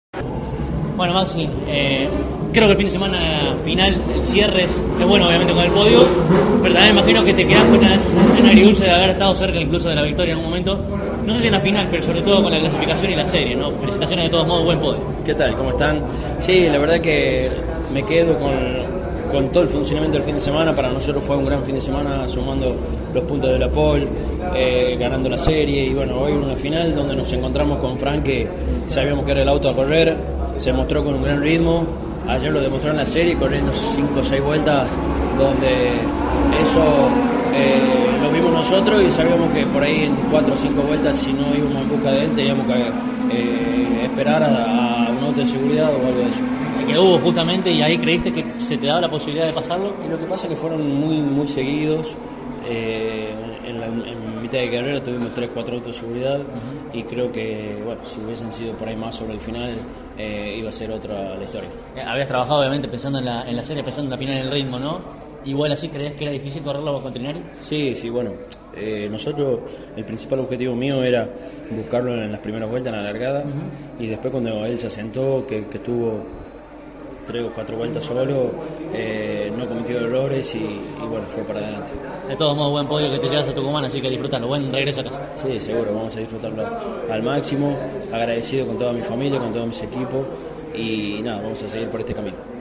CÓRDOBA COMPETICIÓN estuvo presente en el evento y, al término de la competencia definitiva de la divisional menor, dialogó con cada uno de los protagonistas del podio, así como también del cordobés mejor ubicado al término de la prueba.